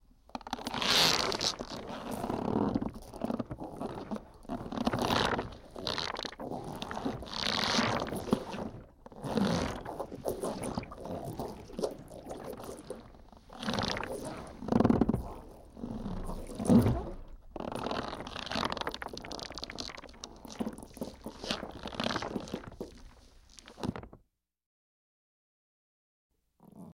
Rubber Stretches; Rubber Processed Stretching And Bending. - Cartoon, Stretchy Rubber